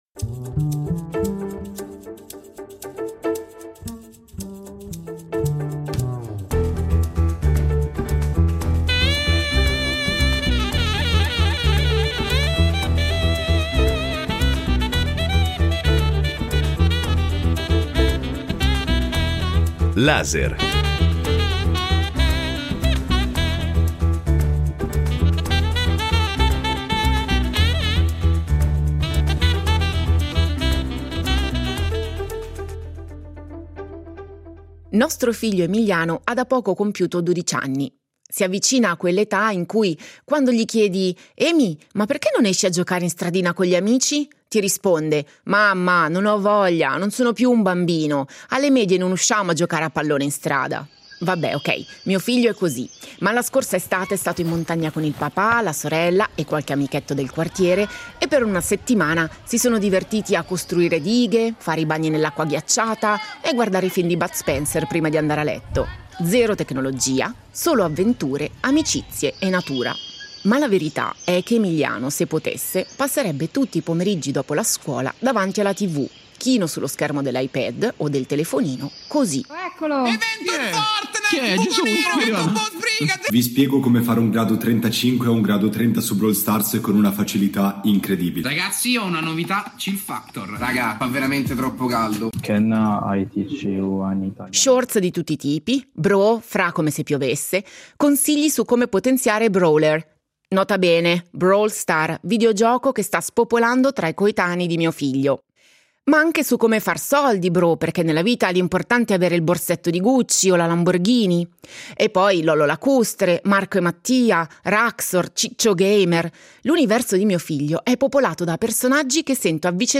psicologo e psicoterapeuta, specializzato in forme di malessere legate al digitale